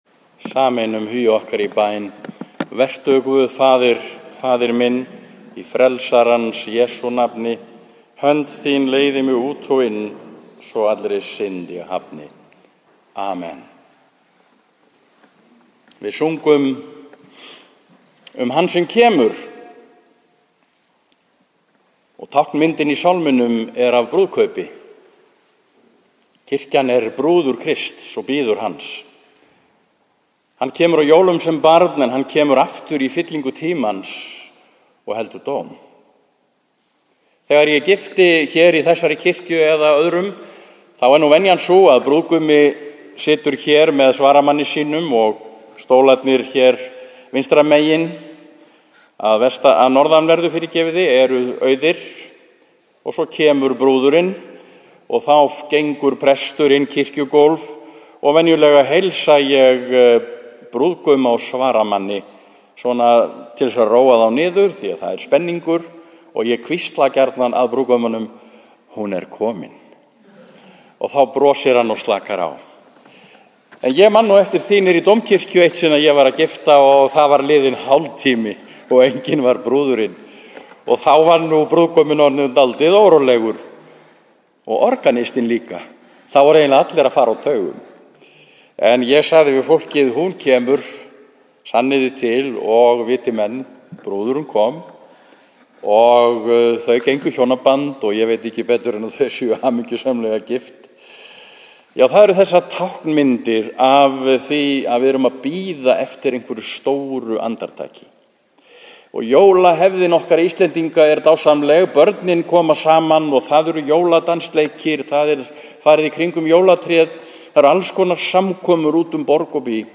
4. sunnudag í aðventu 22. des. var messa í Neskikju kl. 11.
Hugvekju dagsins er hægt að hlusta á hér fyrir neðan: